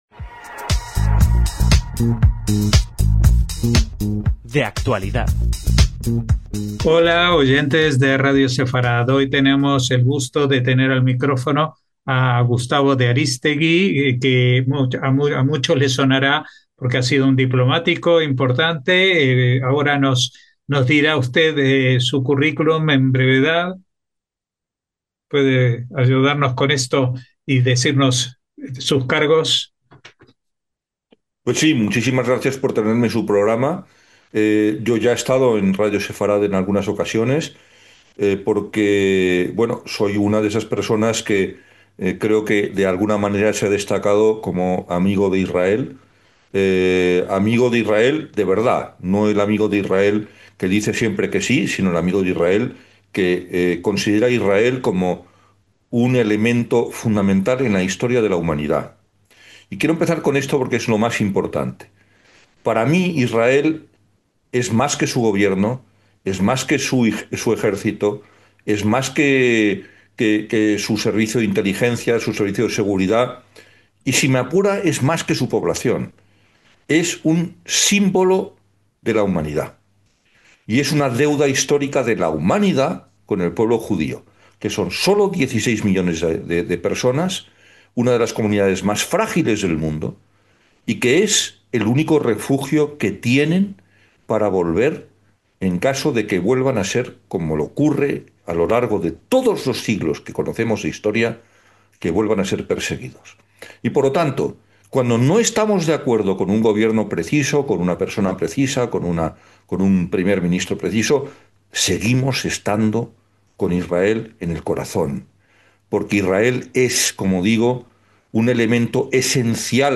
DE ACTUALIDAD - Esta entrevista con el diplomático español Gustavo de Arístegui no tiene ninguna palabra que sobre.